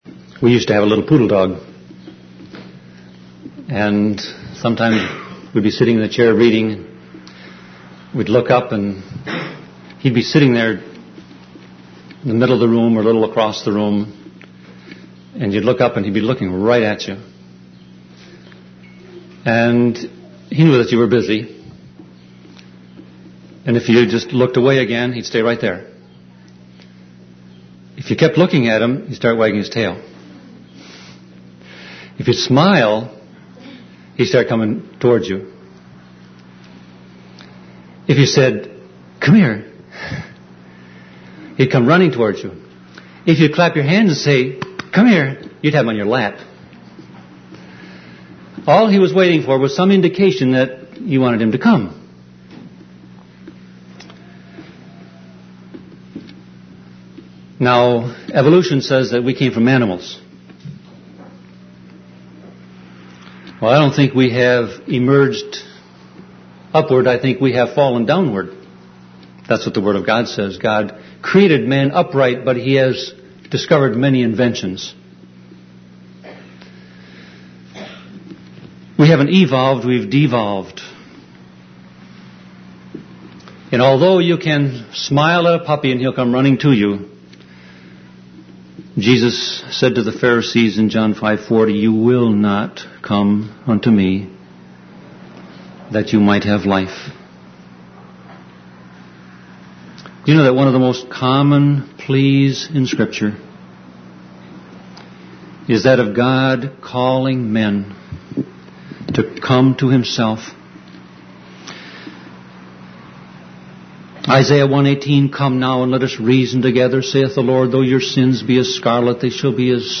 Sermon Audio Passage